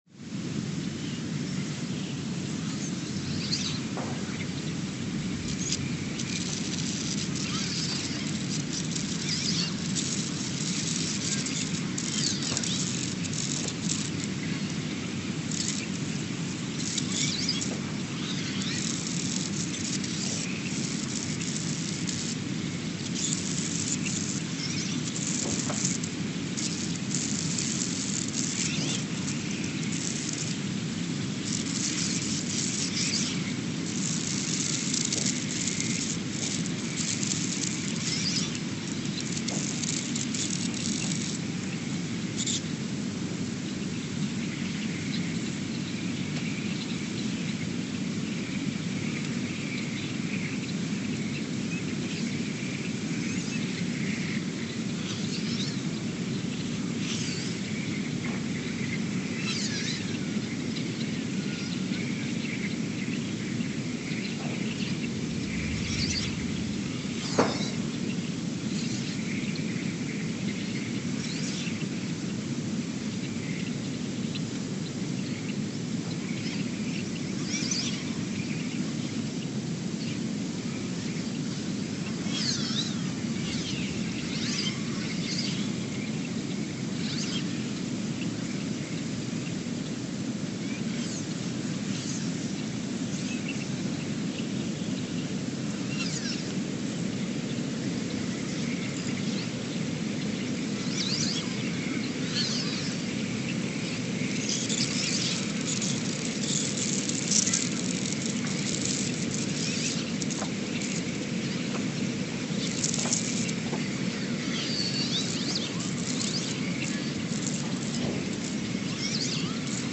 Ulaanbaatar, Mongolia (seismic) archived on September 25, 2023
No events.
Station : ULN (network: IRIS/USGS) at Ulaanbaatar, Mongolia
Sensor : STS-1V/VBB
Speedup : ×900 (transposed up about 10 octaves)
Loop duration (audio) : 03:12 (stereo)